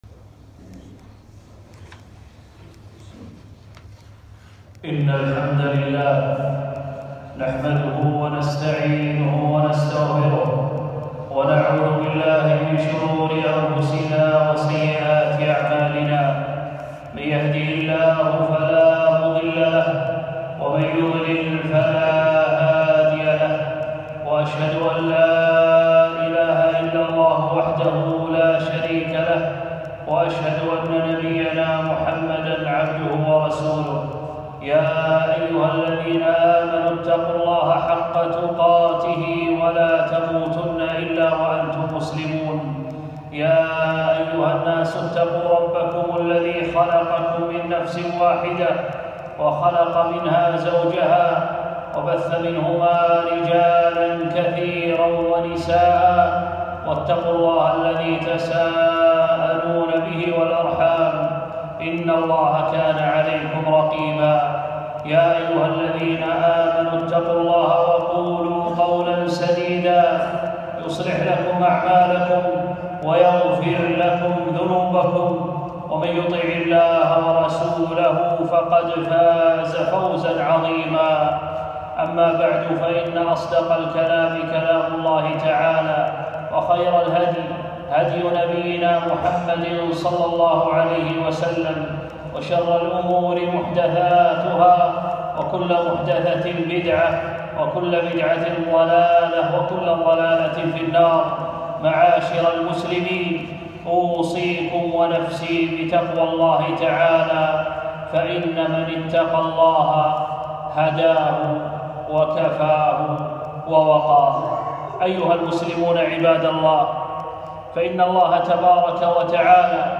خطبة - بعض التبيهات في الطهارة